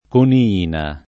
coniina [ koni- & na ] → conina